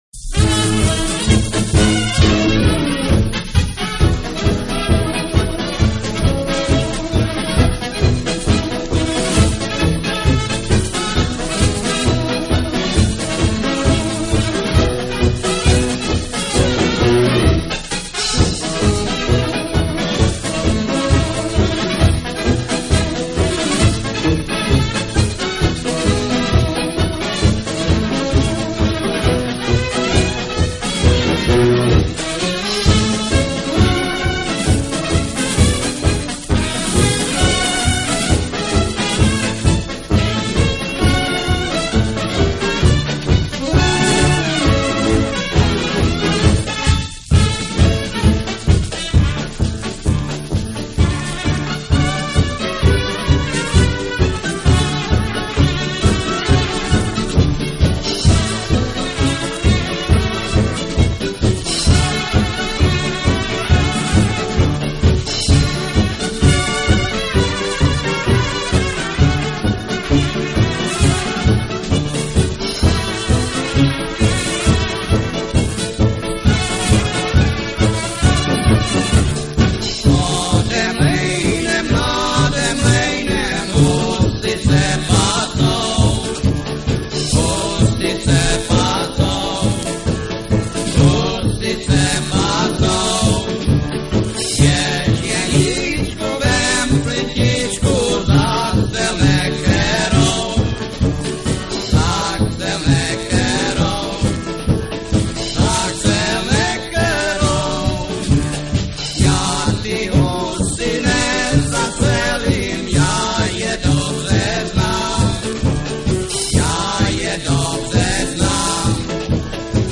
(instrumental version)
Commentary 8.